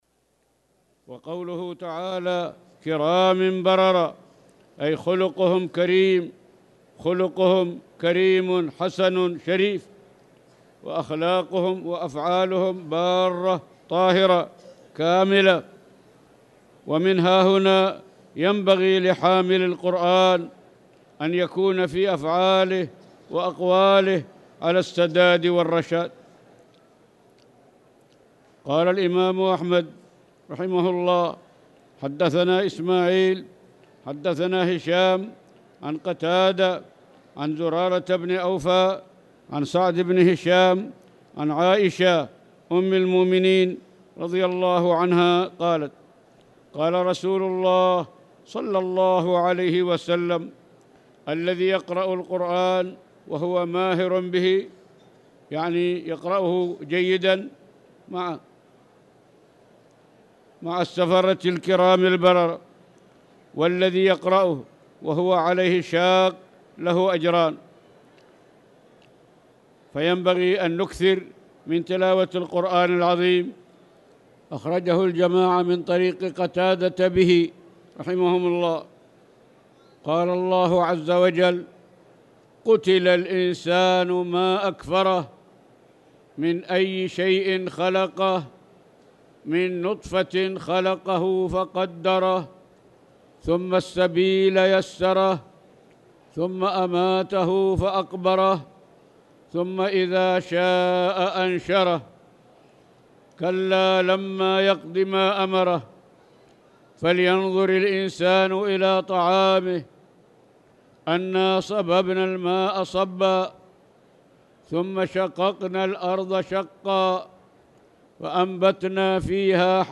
تاريخ النشر ٦ شعبان ١٤٣٧ هـ المكان: المسجد الحرام الشيخ